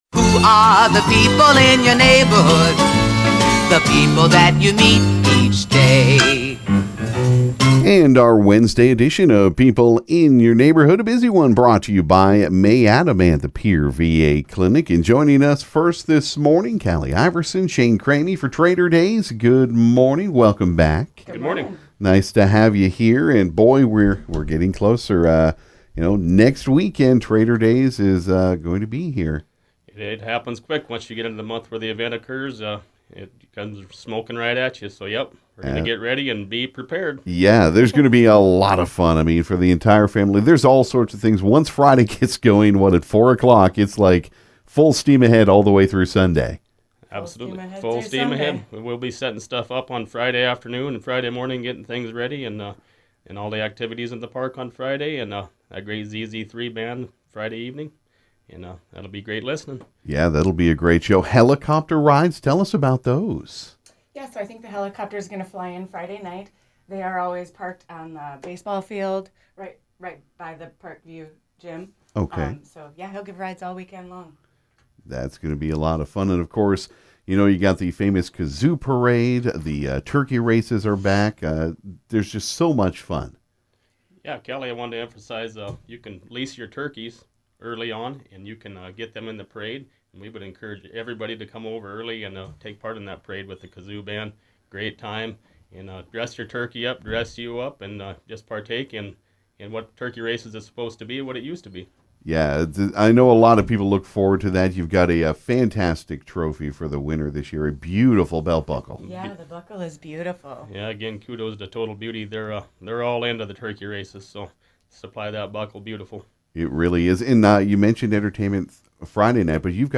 This morning in the KGFX studio there was a myriad of guests.
Also some members of the Prairie Winds 4-H club came to talk about the 4-H Achievement Days coming up on Monday (Aug. 8) and all that will be going on.